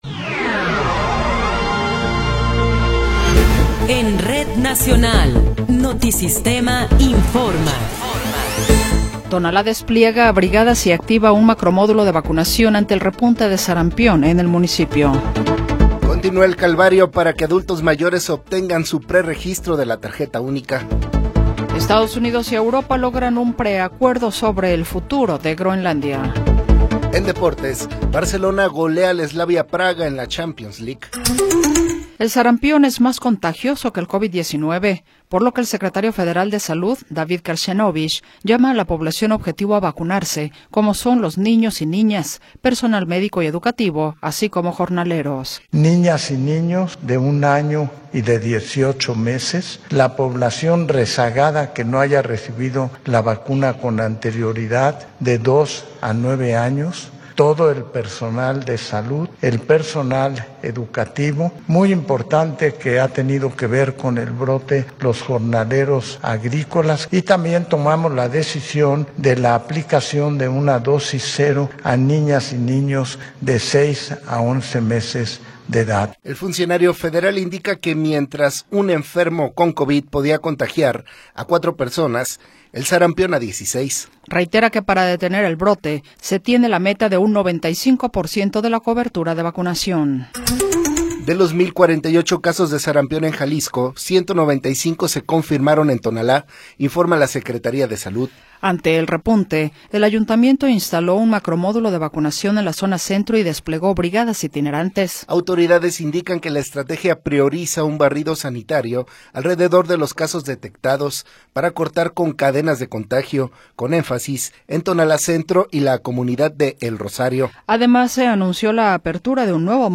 Resumen informativo Notisistema, la mejor y más completa información cada hora en la hora.